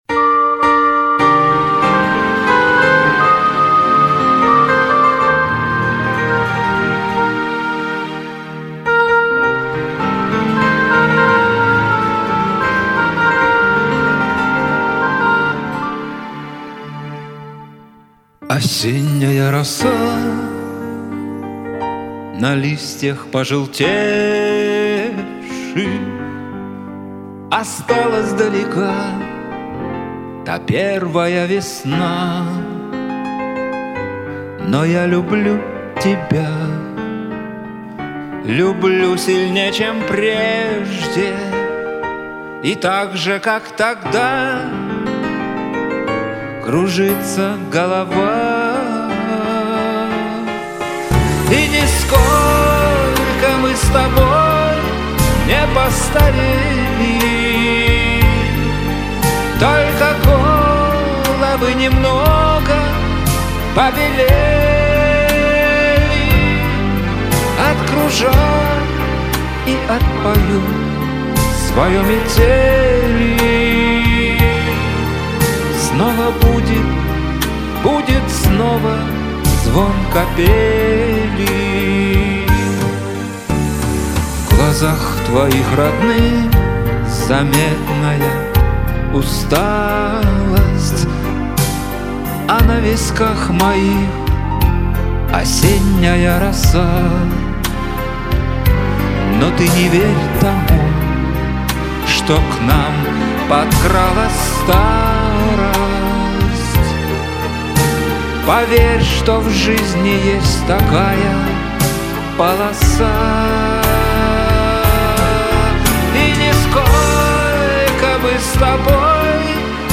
спел хорошо, но слегка прохладно